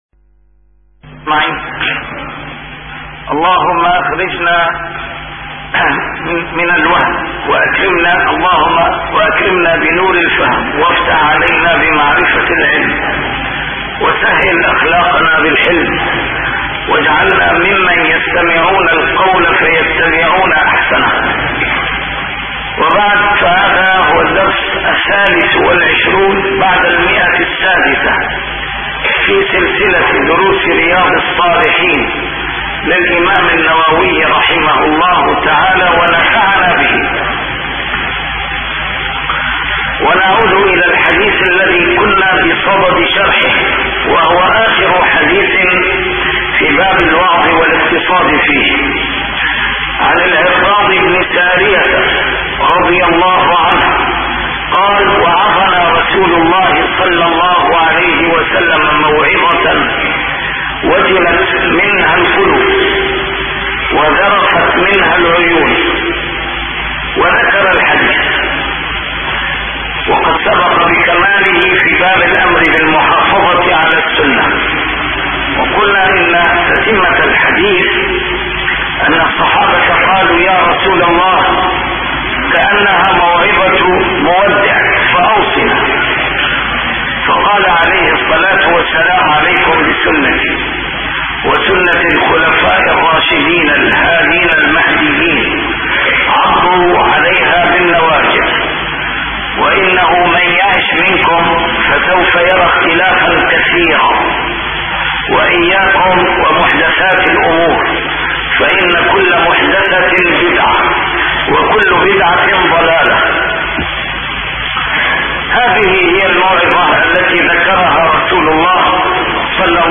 A MARTYR SCHOLAR: IMAM MUHAMMAD SAEED RAMADAN AL-BOUTI - الدروس العلمية - شرح كتاب رياض الصالحين - 623- شرح رياض الصالحين: الوعظ والاقتصاد فيه